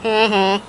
Heh Heh Sound Effect
heh-heh.mp3